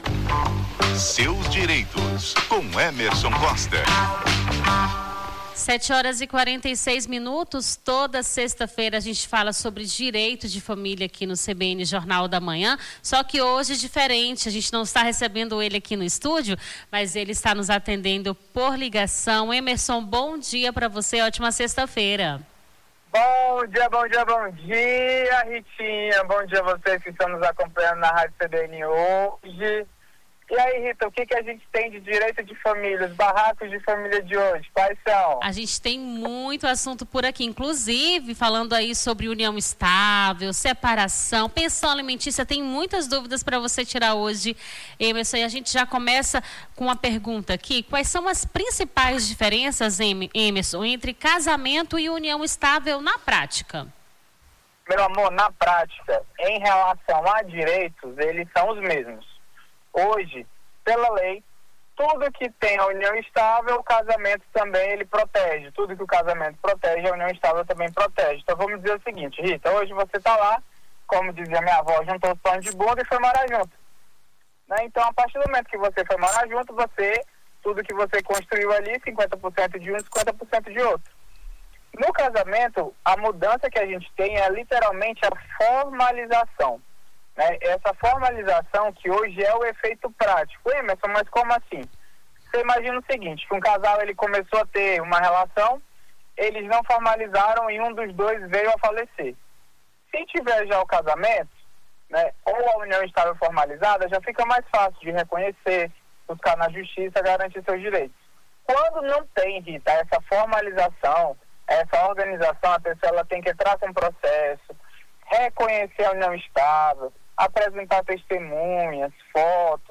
Seus Direitos: advogado tira dúvidas sobre direito de família